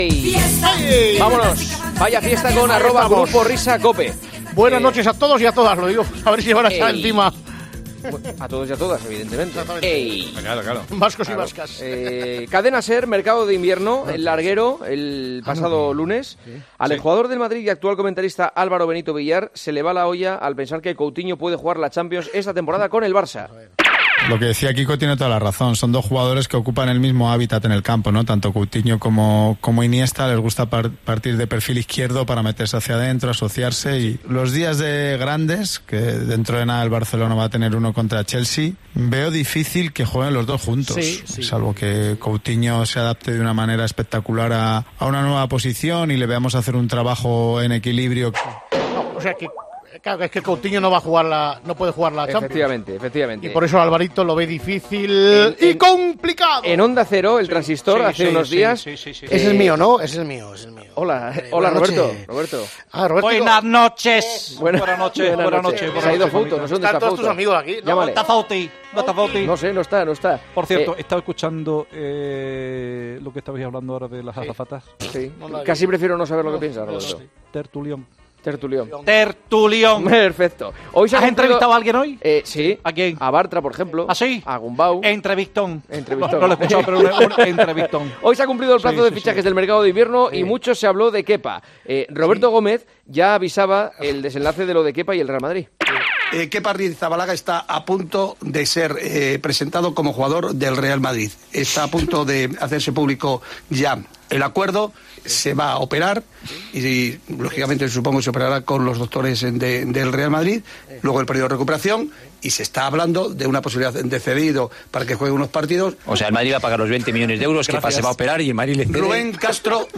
El otro de la Morena llama a Aginagalde después de la broma que le gastó el jugador de la selección.